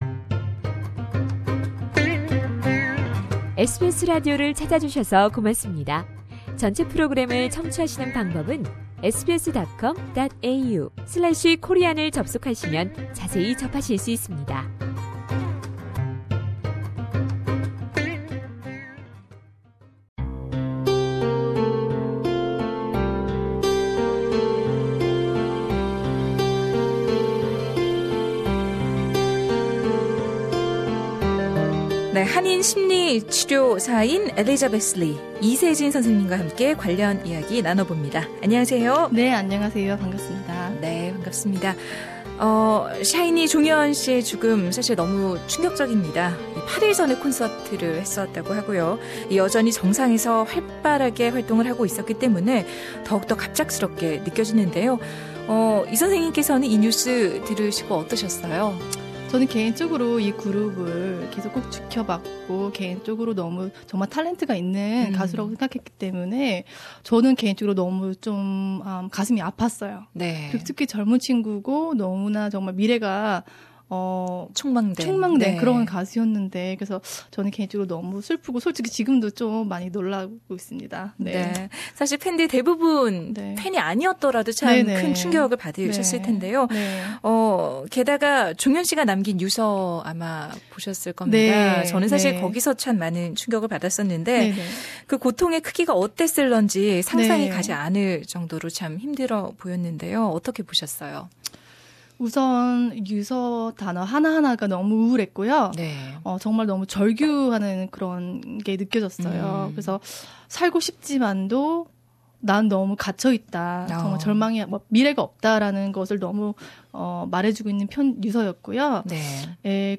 Full interview is available on podcast above.